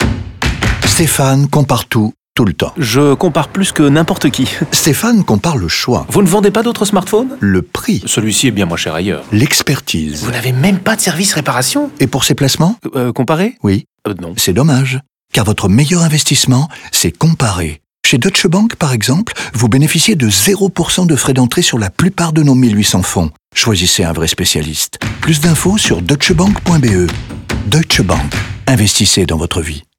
N’oublions pas la radio, mettant en scène Stéphane, un homme qui compare tout, tout le temps, sauf pour ses investissements, bien évidemment.
DeutscheBank-LeComparateur-Radio-FR-30s-Fonds-051217.mp3